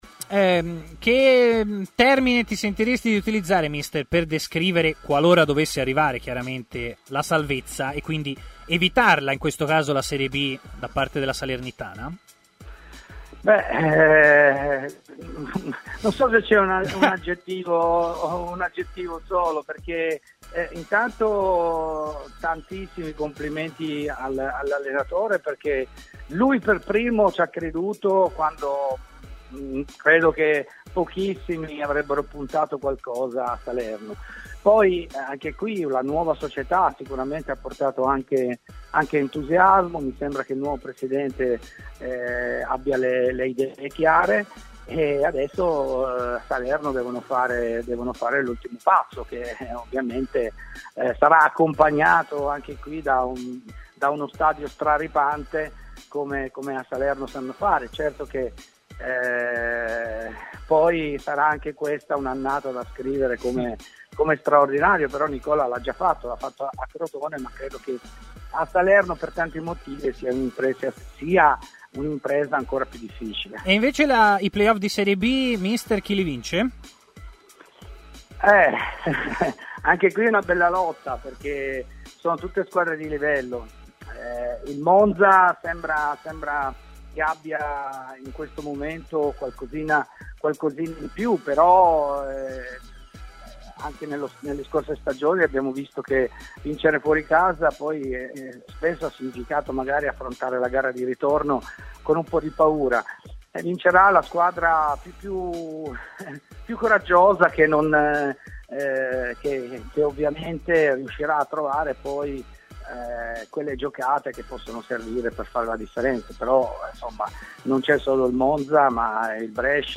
Intervenuto ieri a Stadio Aperto, trasmissione di TMW Radio